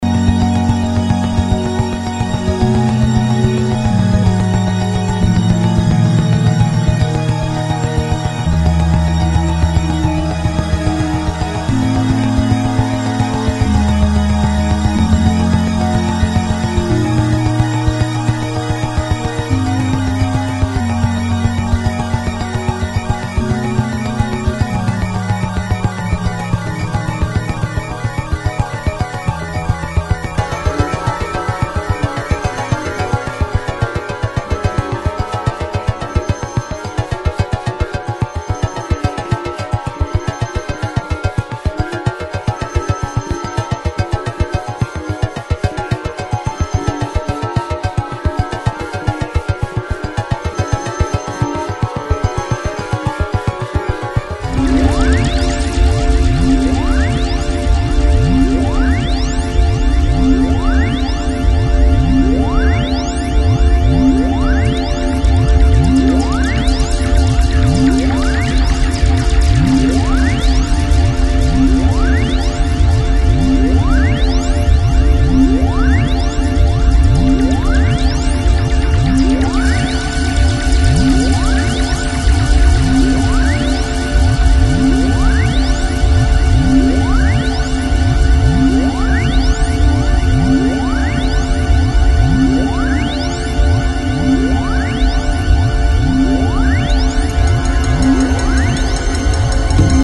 As an electronic symphonic music creation